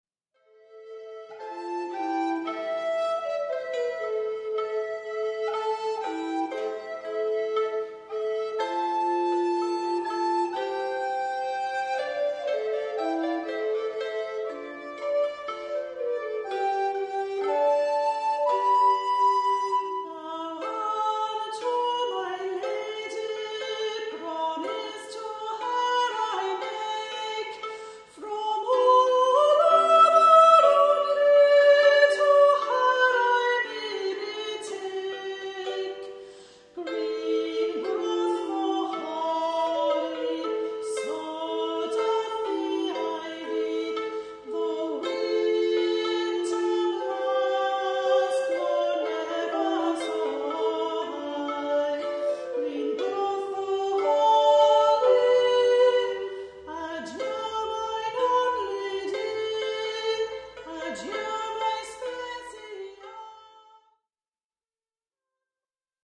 Green Groweth the Holly – Henry VIII. Unlike Greensleeves, this charming love song is genuinely the work of Henry VIII, probably written when he was in his teens under the tutelage of a music master. We perform it using recorders and lap harp.